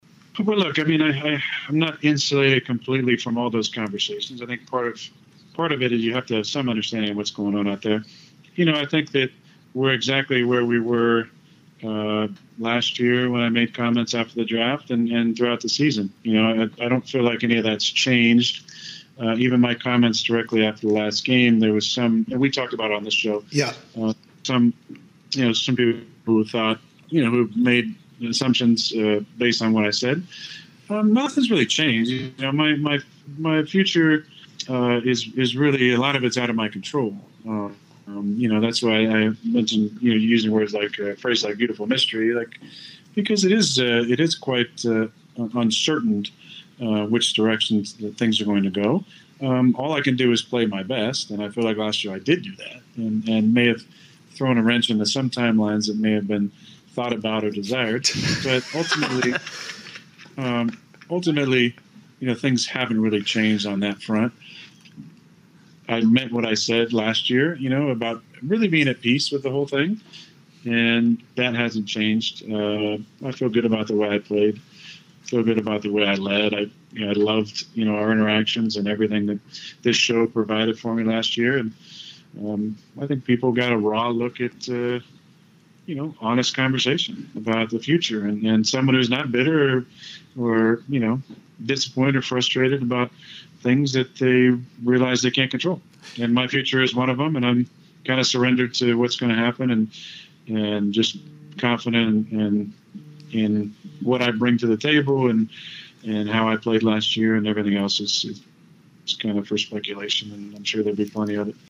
Back on April 5th, Rodgers made one of his regular appearances on the Pat McAfee show and reiterated his thoughts on his past, present and future:
rodgers-mcafee-4-5.mp3